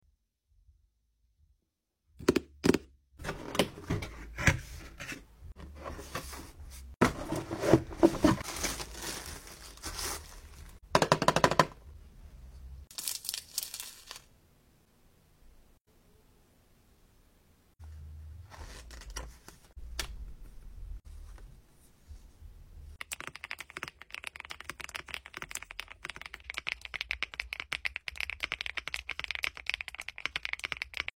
I love some crispy and clear ass ASMR! Especially typing sounds!